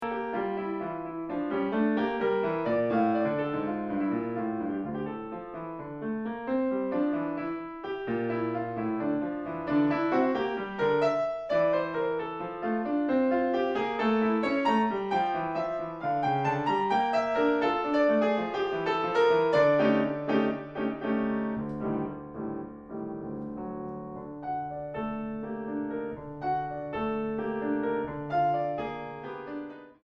Piano Bösendorfer 290 Imperial.